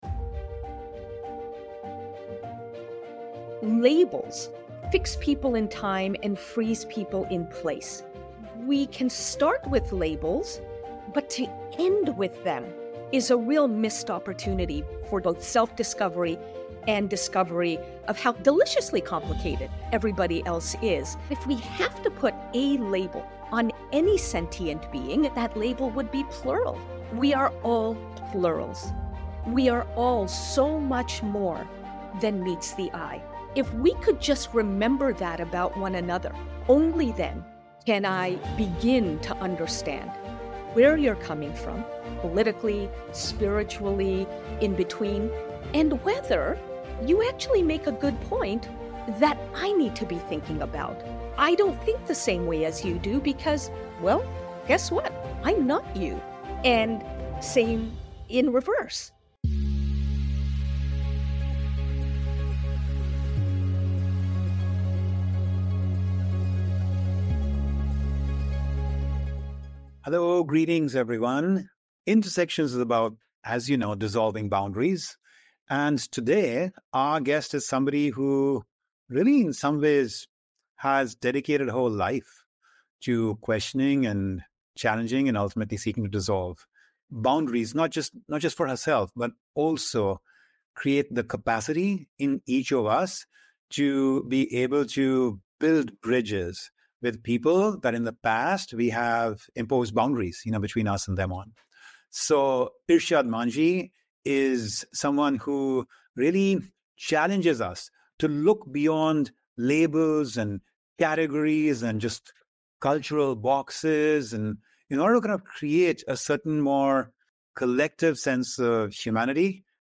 Find out from the recipient of Oprah’s “Chutzpah Award” for boldness, Irshad Manji, in conversation